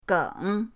geng3.mp3